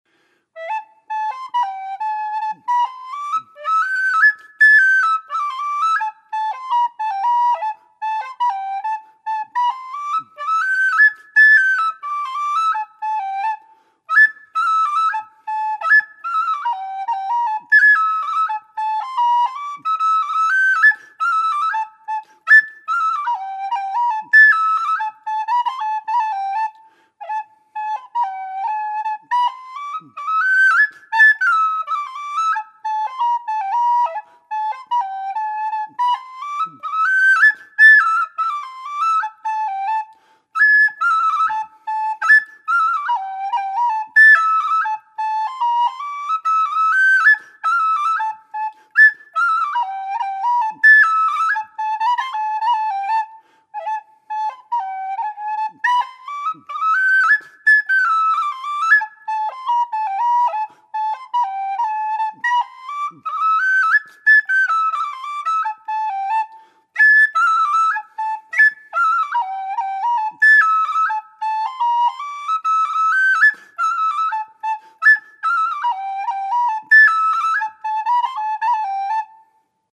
C whistle
made out of thin-walled aluminium tubing with 14mm bore